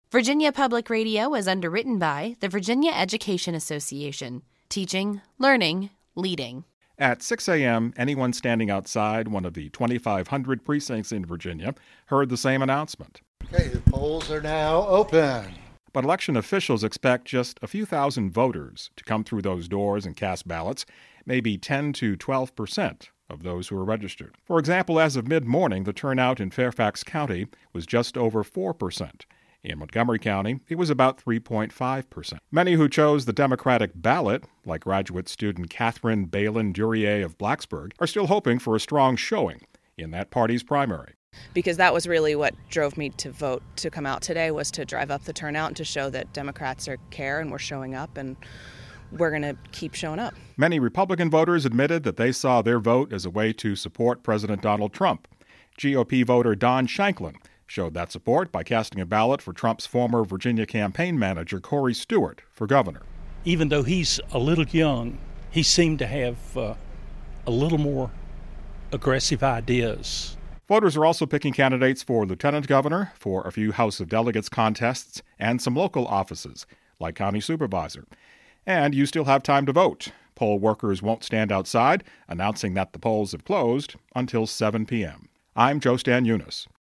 talked to some voters in western Virginia